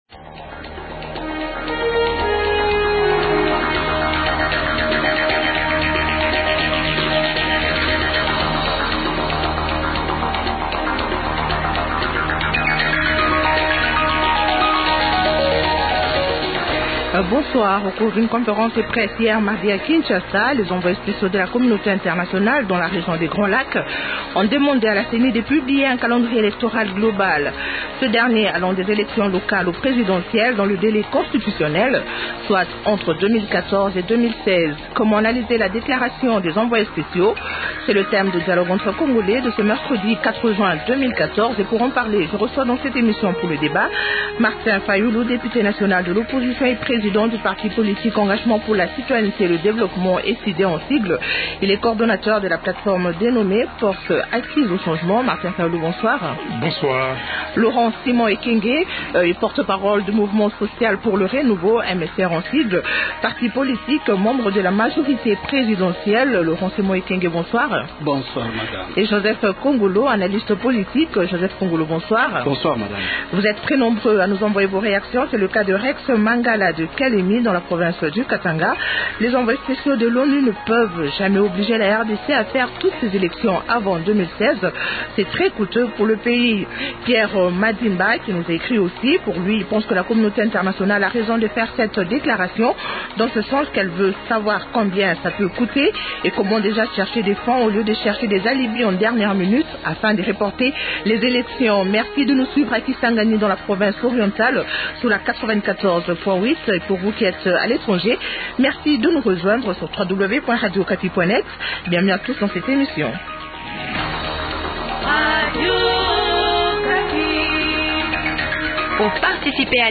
- Comment analyser la declaration des envoyés spéciaux ? Invités -Martin Fayulu, Député national de l’opposition et président du parti politique Engagement pour la citoyenneté et le développement (Ecidé) et Coordonnateur de la plate-forme dénommée Forces acquises au changement (Fac).